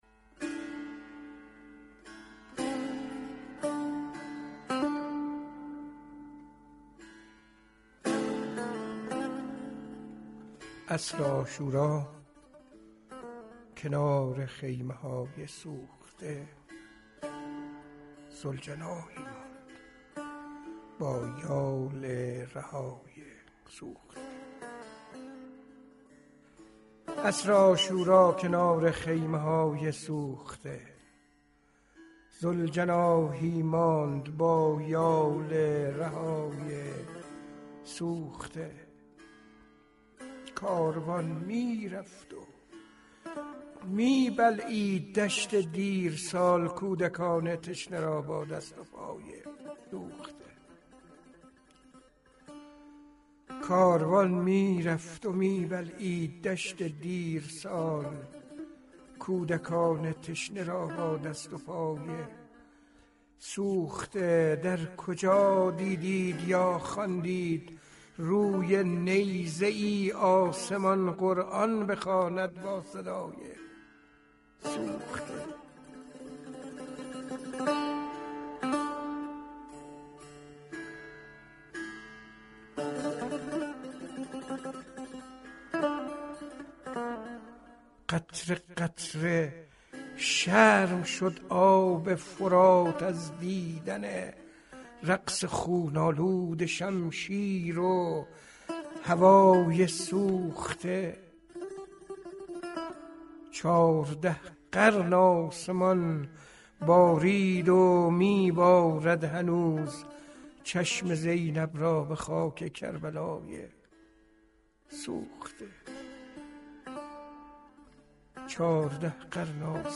نی‌نامه را با صدای شاعران عاشورایی بشنوید
كتاب صوتی نی‌نامه شامل خوانشی از مجموعه اشعار عاشورایی با صدای شاعران منتشر شد.
این كتاب صوتی گنجینه‌ ی 29 شعر عاشورایی از 9 شاعر معاصر است. خوانش شعر توسط خود شاعر لذت شنیدن این اثر را دو چندان كرده است.